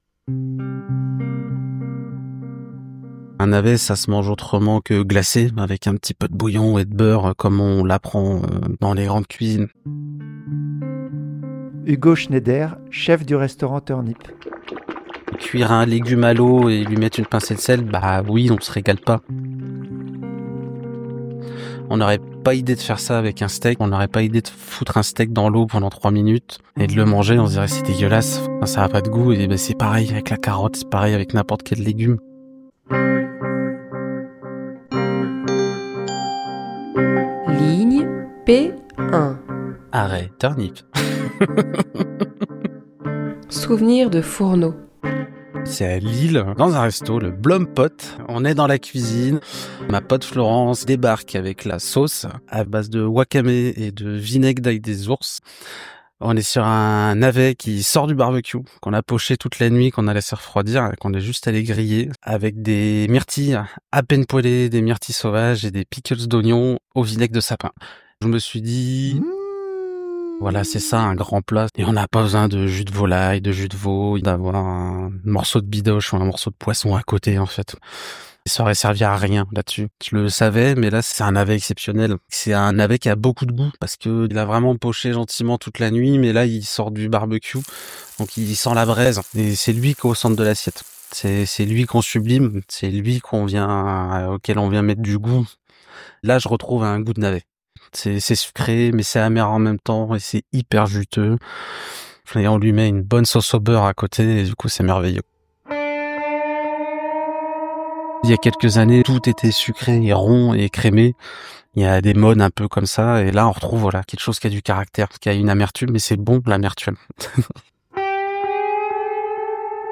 À chaque étape du parcours, un QR code à flasher pour entendre une pastille sonore de deux minutes à écouter sur place ou en avançant ; ainsi qu’une carte qui indique la manière de rejoindre le point suivant.
Exemple de son pour une balade dans laquelle des restaurateurs évoquent leurs souvenirs de fourneaux :